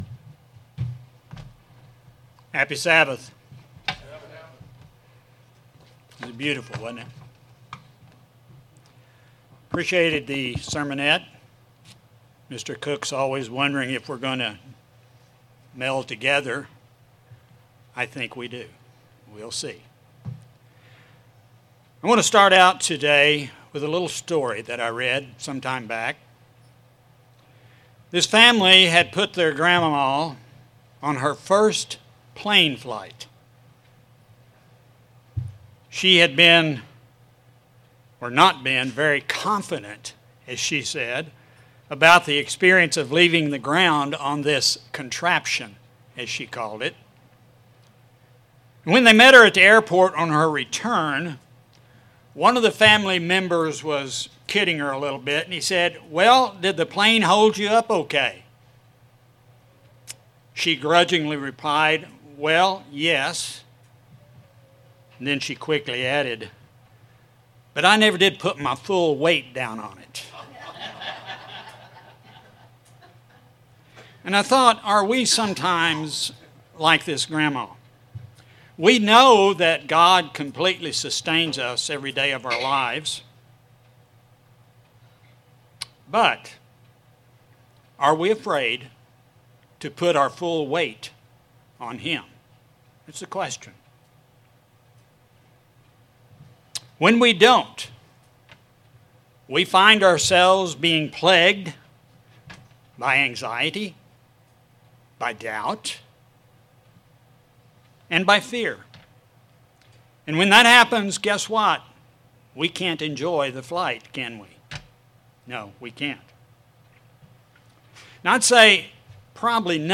Sermons
Given in Springfield, MO